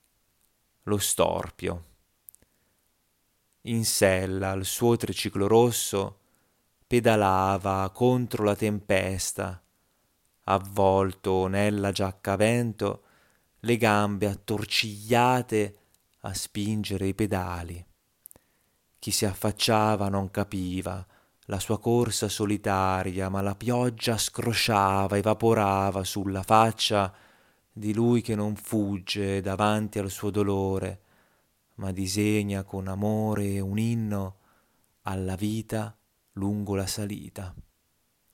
Lecture poétique